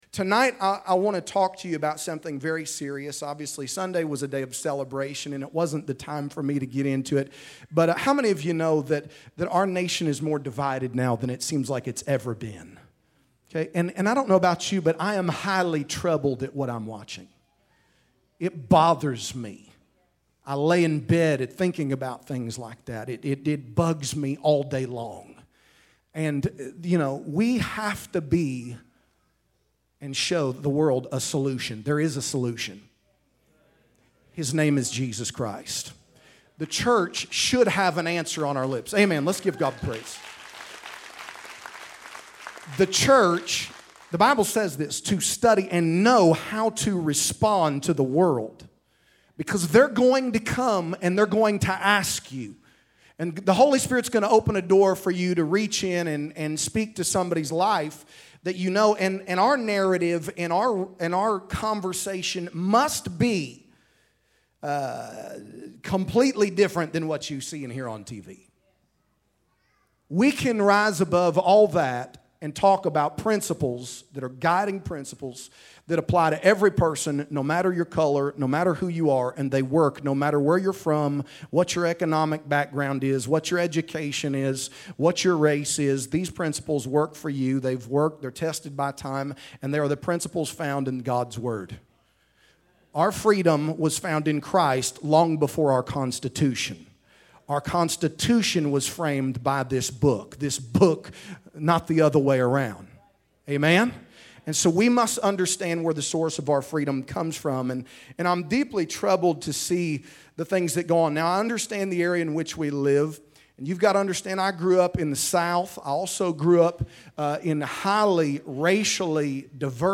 Download audio In this dynamic message